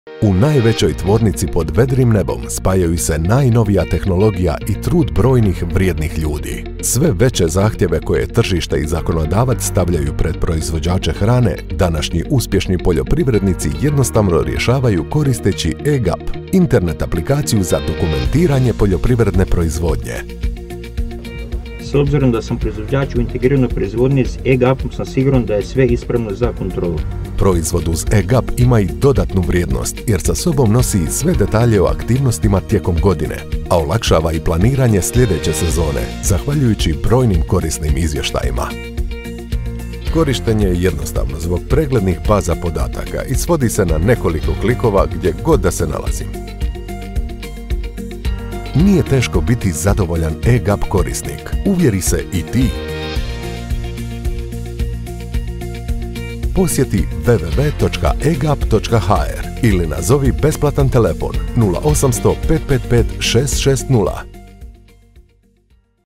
Explainer Videos
I am a professional Croatian voice-over artist, speaker, narrator and producer with over 20 years of experience with my own studio.
Middle-Aged
BassDeep
WarmAuthoritativeConversationalCorporateFriendly